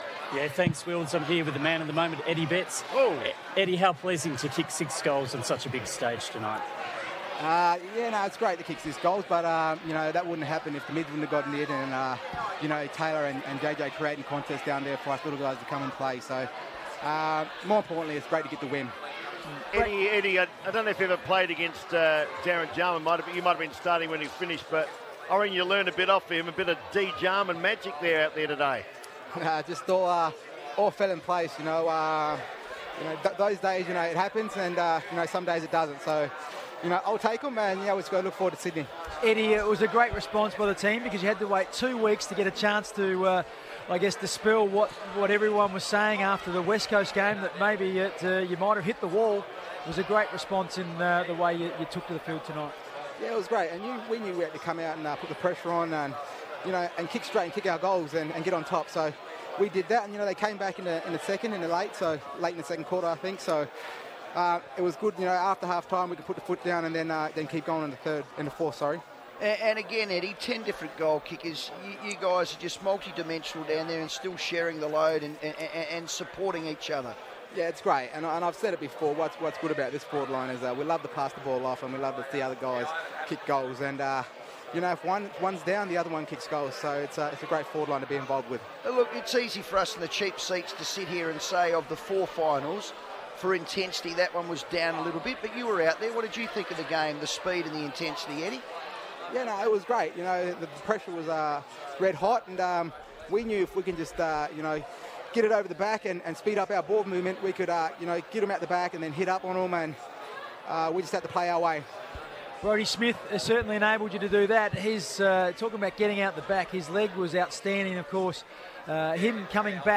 Adelaide forward Eddie Betts talks to FIVEaa following his six-goal performance in the Crows elimination final victory over North Melbourne